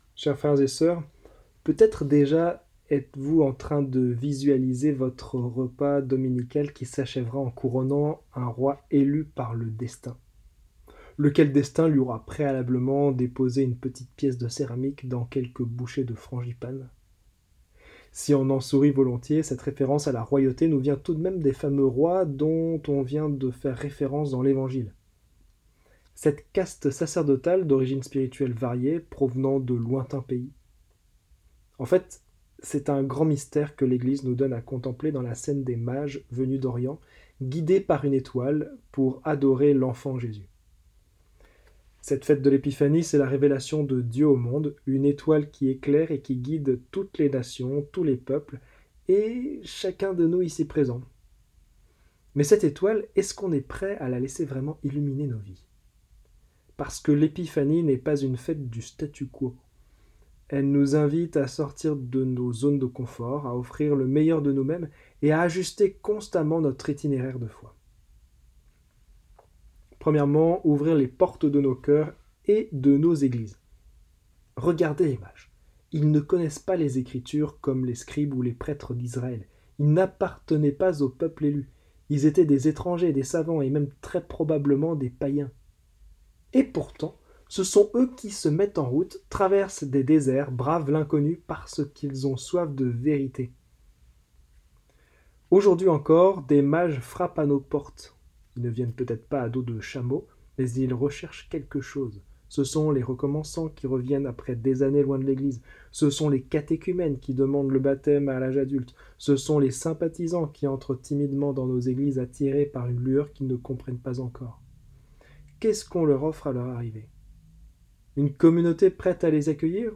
Homélies en 3 points